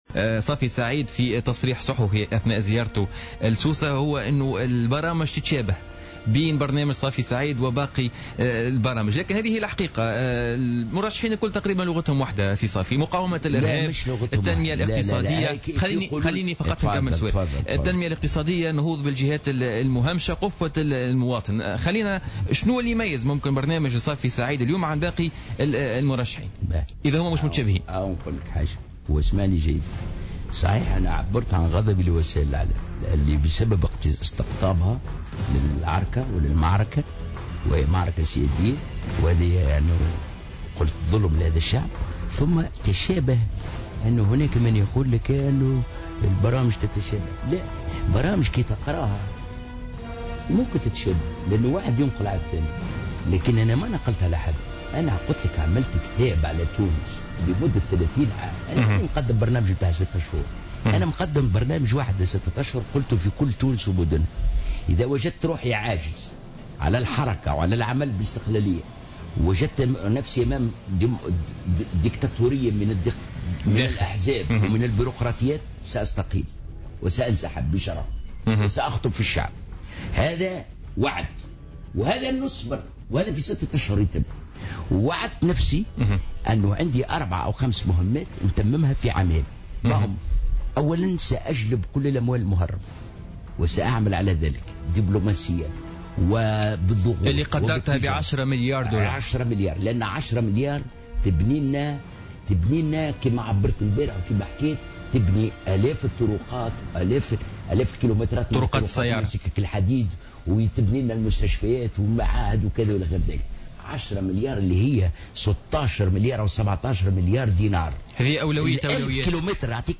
أكد المترشح للإنتخابات الرئاسية صافي سعيد ضيف برنامج بوليتيكا اليوم الإثنين 17 نوفمبر 2014 أنه سيساهم من موقع رئيس الجمهورية في وضع تونس على سكة القرن الواحد والعشرين وسكة الحداثة الفعلية التي حادت عنها على حد قوله.